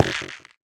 minecraft / sounds / block / stem / step4.ogg
step4.ogg